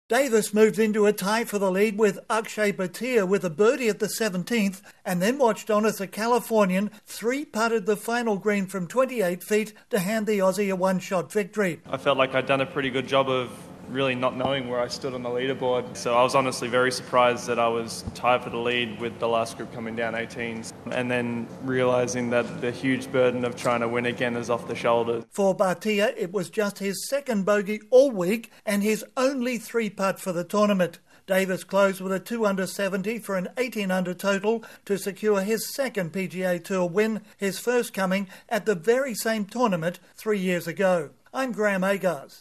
Long hitting Australian Cam Davis posts a dramatic come from behind win at the Rocket Mortgage Classic in Detroit. Correspondent